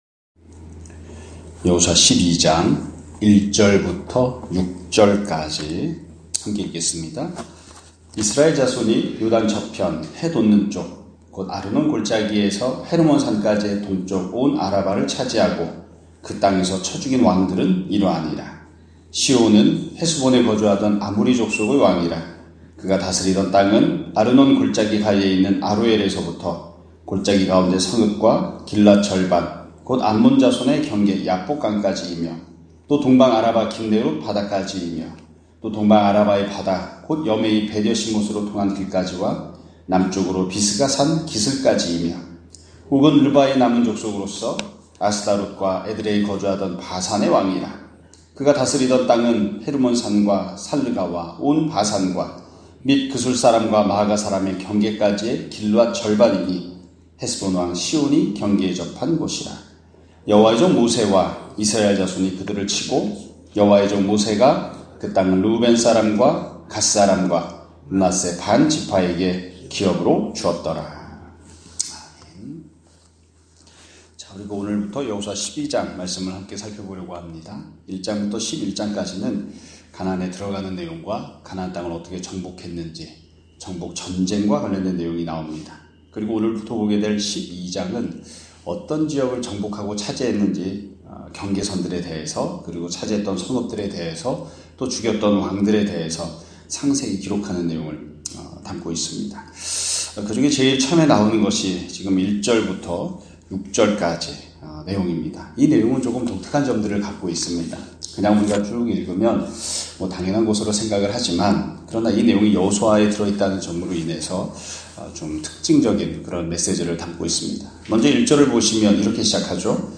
2024년 11월 11일(월요일) <아침예배> 설교입니다.